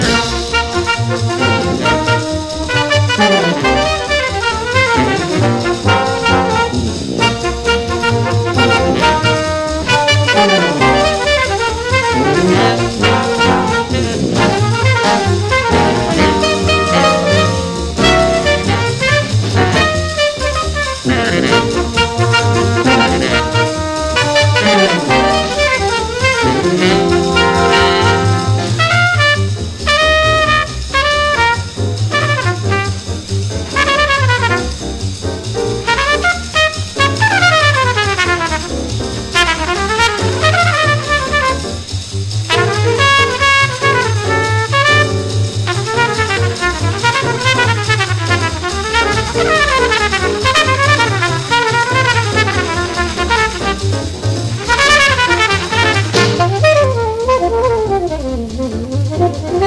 JAZZ / MAIN STREAM / PIANO / EASY LISTENING
しっとり繊細なタッチの美しい
MONO/深溝/US ORIGINAL盤！